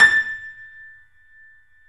Index of /90_sSampleCDs/E-MU Producer Series Vol. 5 – 3-D Audio Collection/3D Pianos/YamaHardVF04